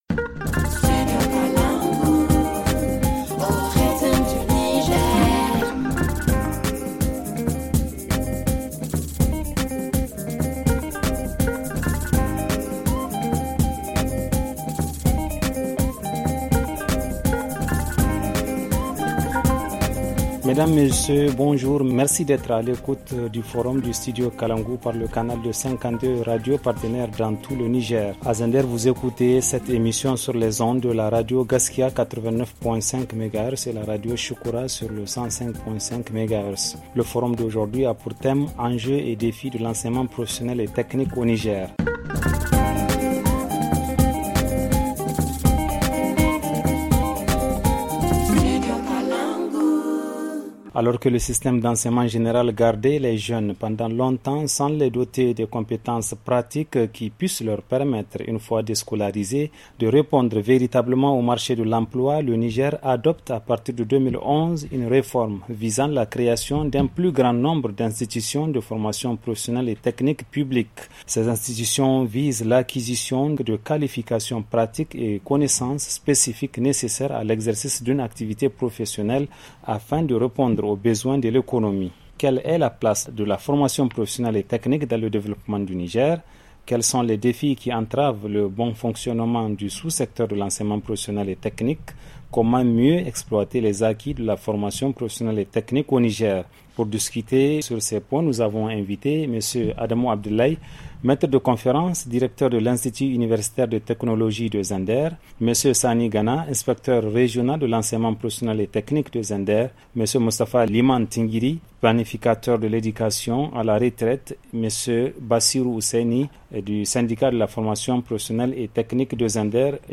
Au Niger pour remédier aux problèmes d’emplois dont souffrent certains élèves déscolarisés, l’Etat a adopté en 2011, une réforme visant la création d’un nombre important d’institutions de formation professionnelle et technique.Dans ce débat, nous allons tenter de comprendre la place de la formation professionnelle et technique dans le développement du Niger ?